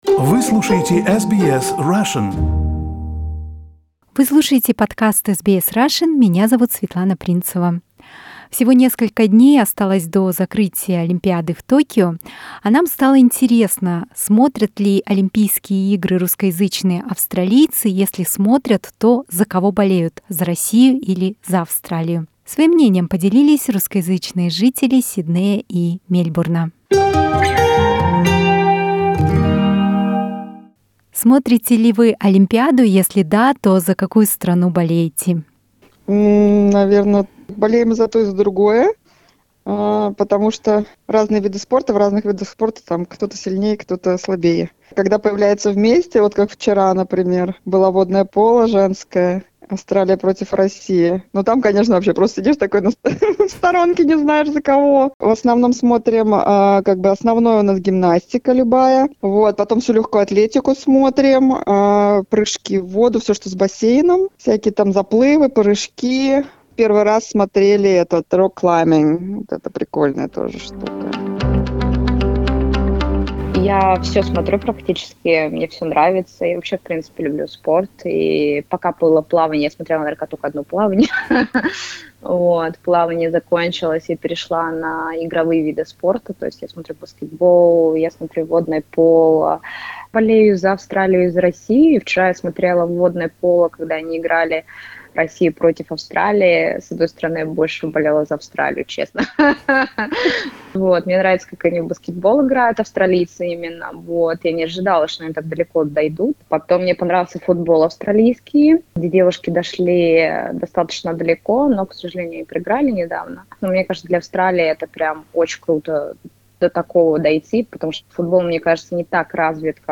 Мы спросили у росскоязычных жителей Австралии, смотрят ли они Олимпийские игры и за какую страну чаще болеют - за Австралию или Россию. А также узнали, что они думают о целесообразности проведения Олимпиады во время пандемии.